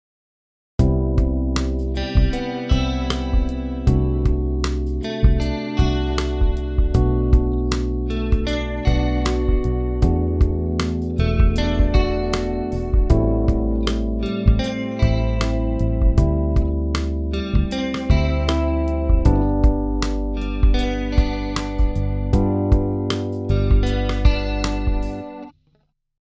プリセット名ではギター向けではないのですが、もしかしたら合うかなぁ～と思って“074 Pad/Slow Synth”を選んでみました。
音に艶が出て広がりましたね。